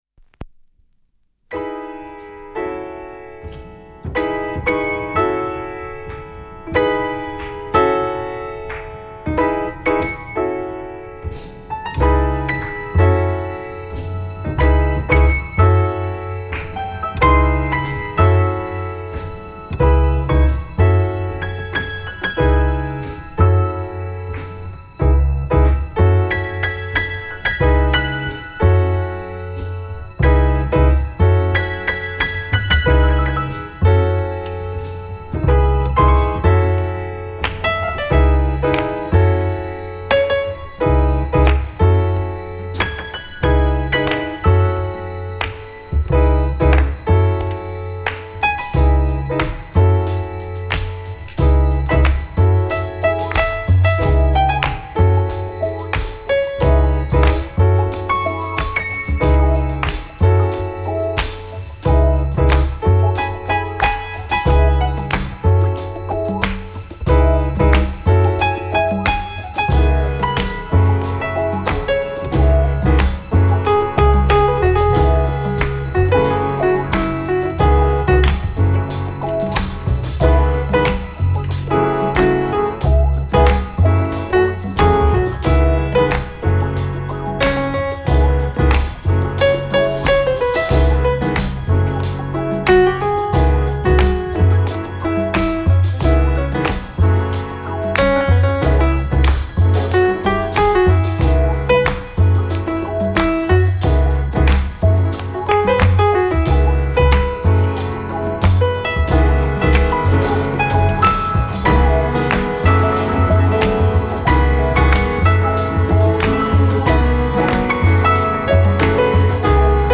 2025年10月の来日時に実際にレコード店を巡り、手に入れたジャズ～周辺レコードを素材に制作。
〈試聴〉※前半10分ダイジェスト https